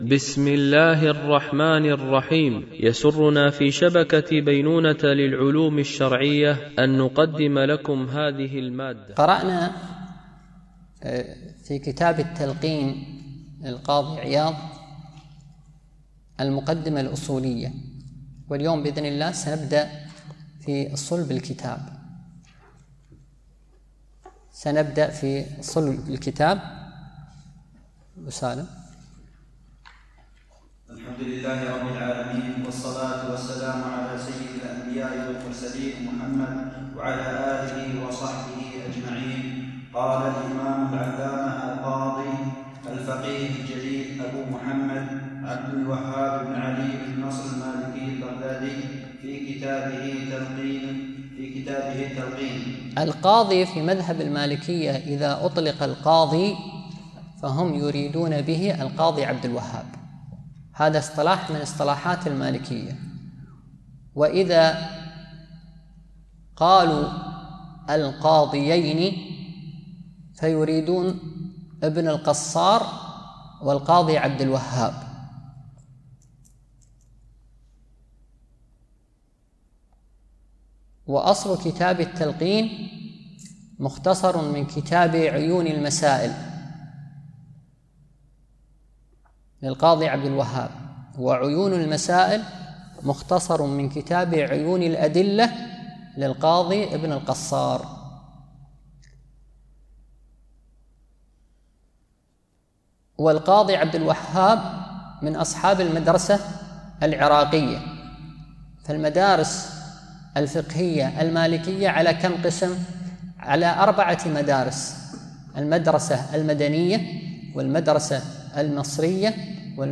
MP3 Mono 44kHz 96Kbps (VBR)